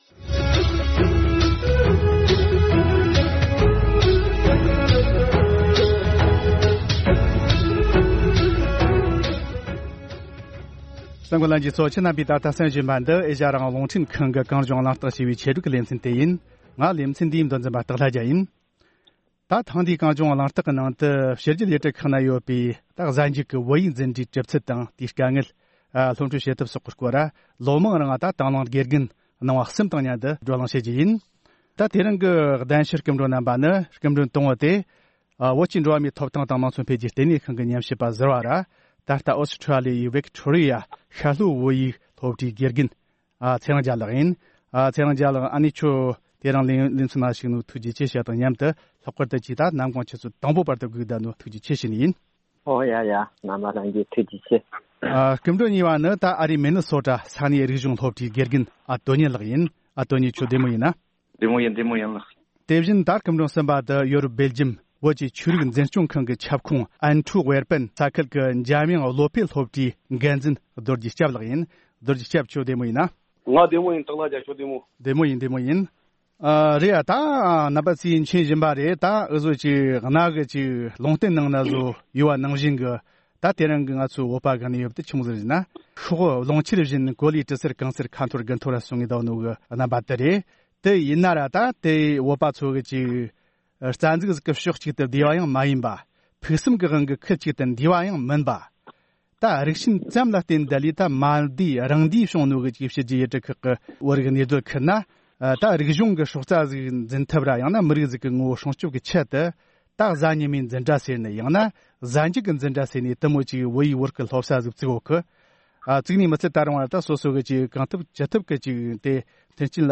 ཕྱི་རྒྱལ་ཡུལ་གྲུ་ཁག་ན་ཡོད་པའི་གཟའ་ཉི་མའི་འཛིན་གྲྭ་ཡང་ན་གཟའ་མཇུག་གི་འཛིན་གྲྭའི་གྲུབ་ཚུལ་དང་དཀའ་ངལ་སོགས་དང་འབྲེལ་བའི་ཐད་གླེང་མོལ་ཞུས་པ།